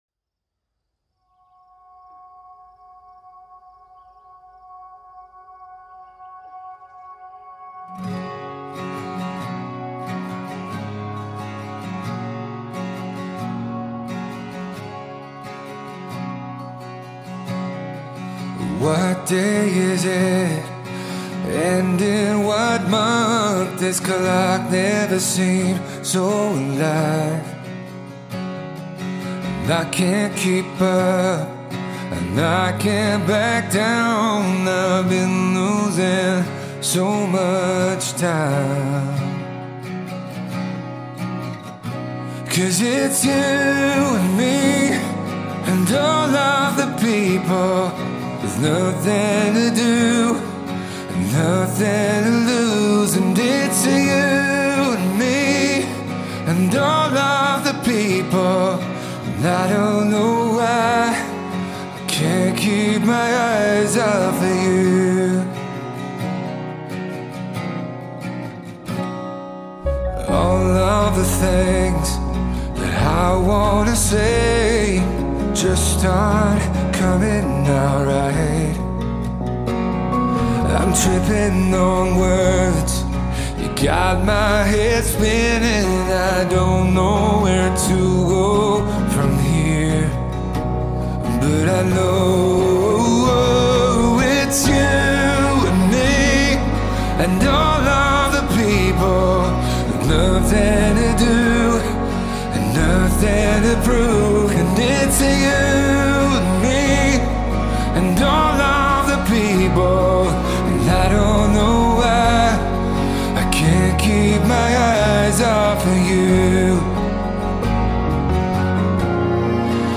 Piano | Guitar | Dual Vocals | DJ